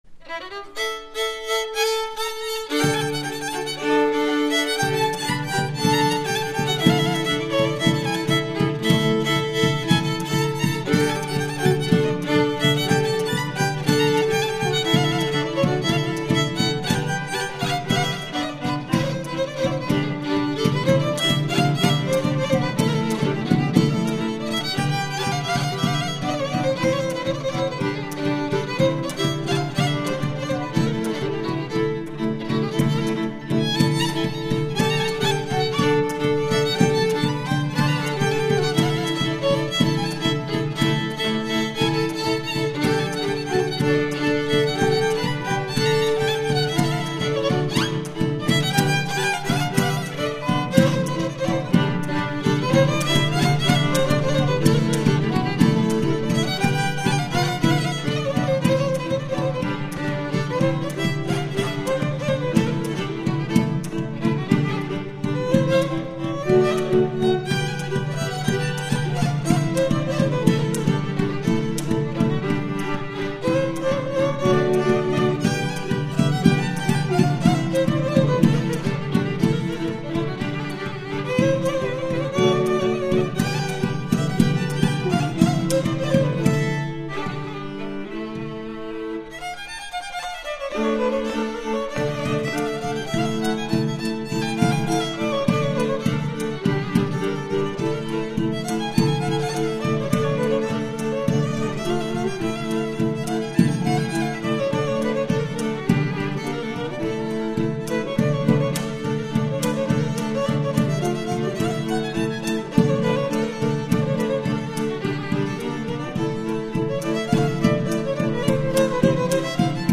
The Hungarian band Muzsikas joins the Takacs String Quartet for a concert of Bartok's own music, and the peasant dances and that inspired him.